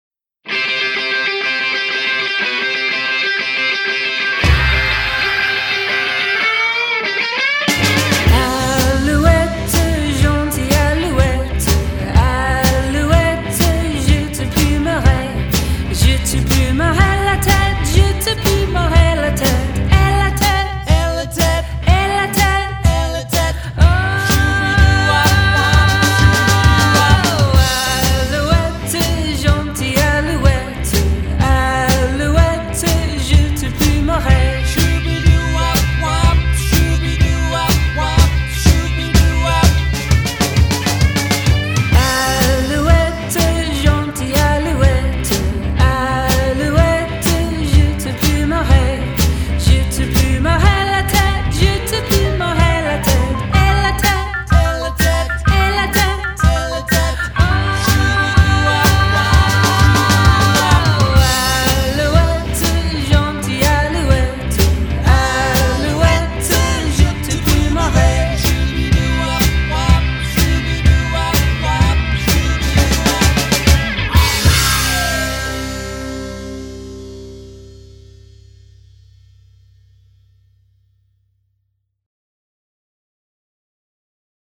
The beloved French-Canadian camp song that everyone knows
Folk